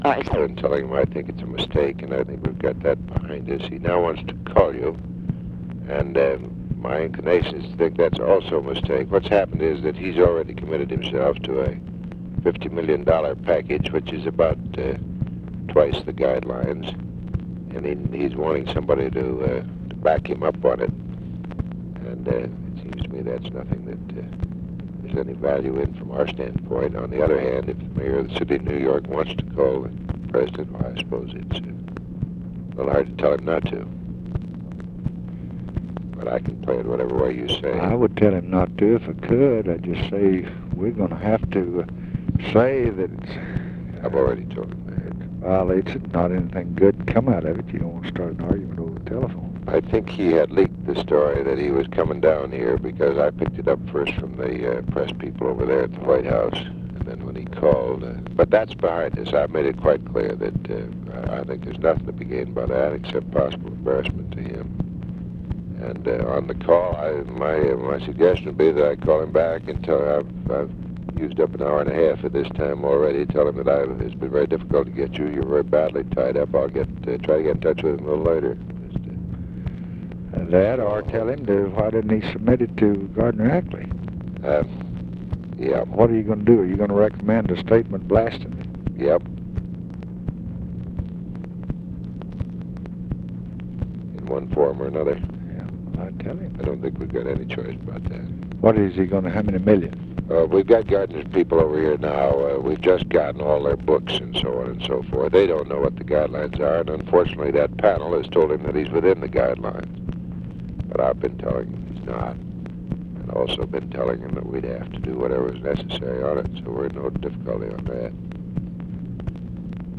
Conversation with WILLARD WIRTZ, January 11, 1966
Secret White House Tapes